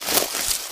STEPS Bush, Walk 08.wav